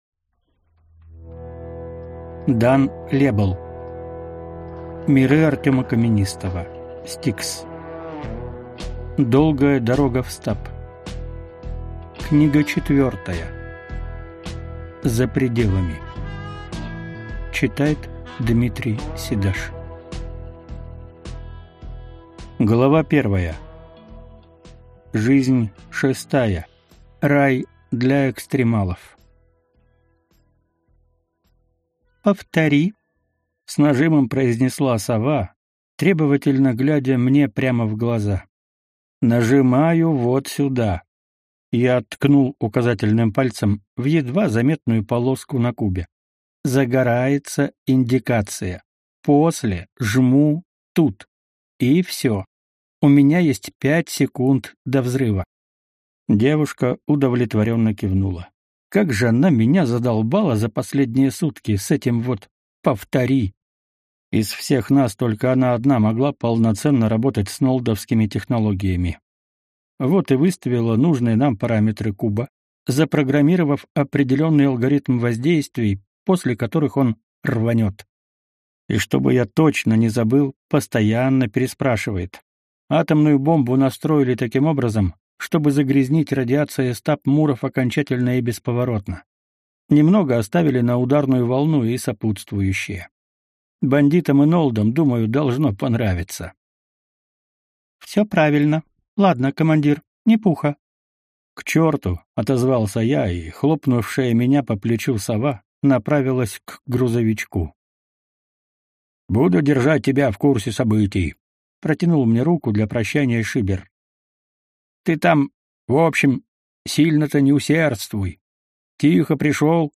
Аудиокнига S-T-I-K-S. Долгая дорога в стаб. Книга 4. За пределами | Библиотека аудиокниг